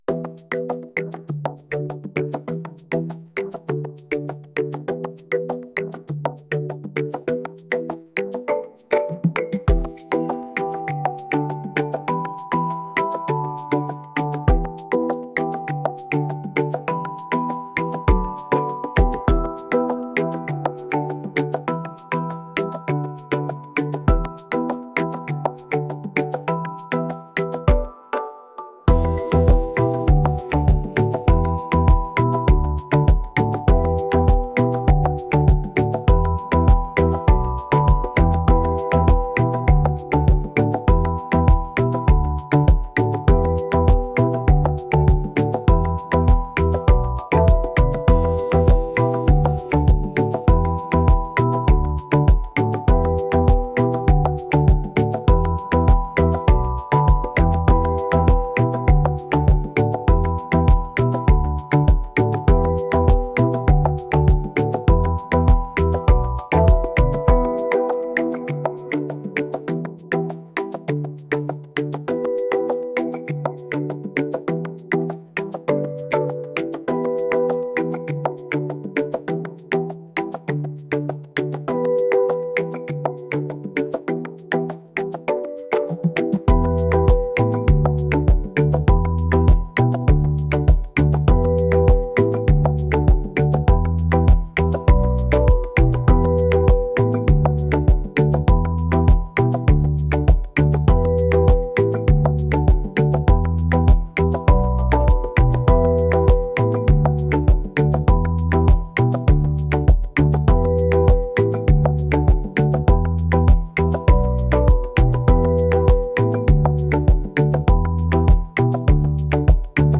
rhythmic | island